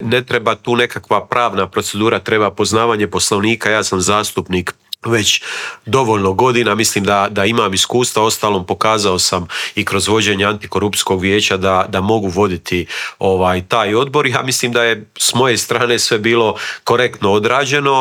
U Intervjuu Media servisa gostovao je upravo Nikola Grmoja i podijelio s nama dojmove sa sjednice.